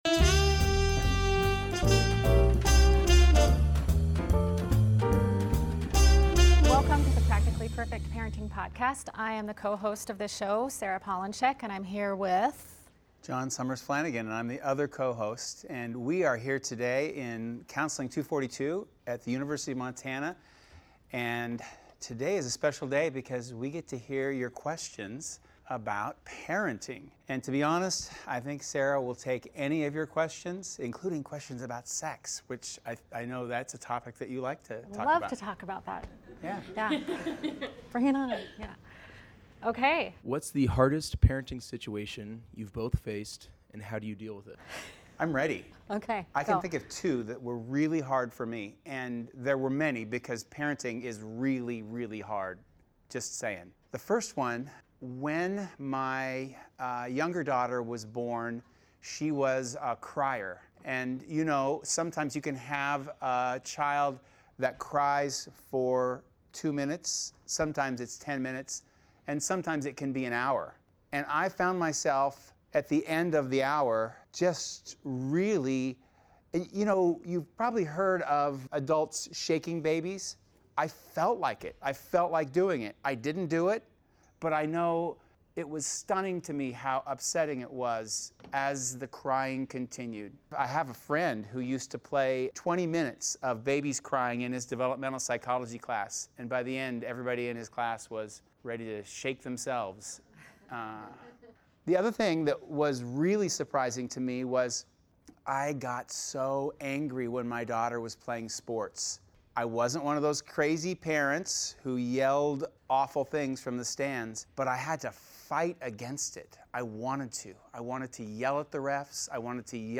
The good news is that we got some fabulous Q and A. The bad news is that most of the students were afraid to approach the microphone.